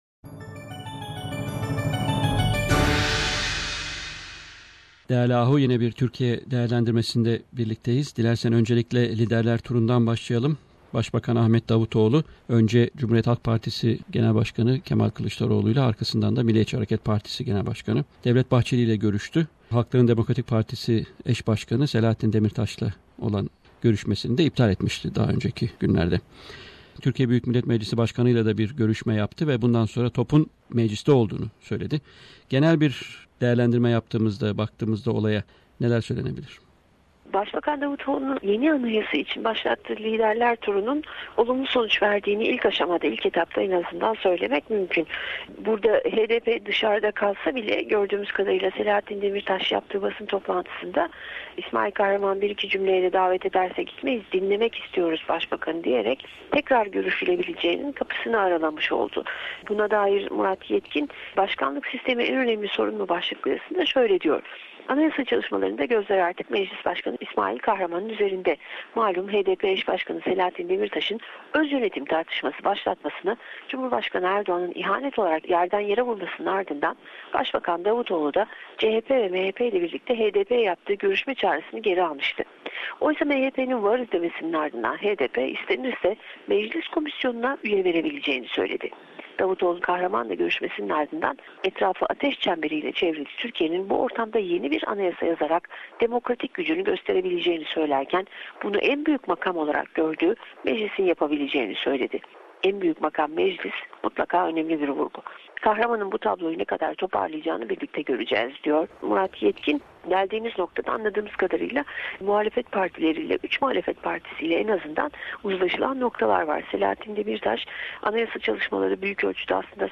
Stringer Report from Istanbul